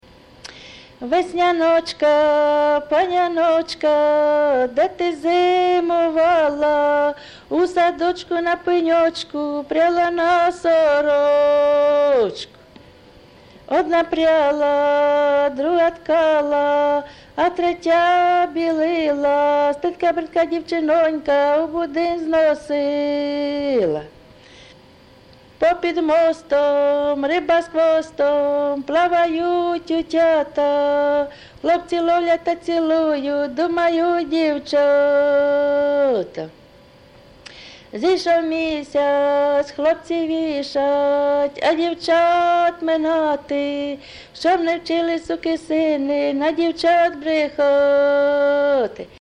ЖанрВеснянки
Місце записус. Яблунівка, Костянтинівський (Краматорський) район, Донецька обл., Україна, Слобожанщина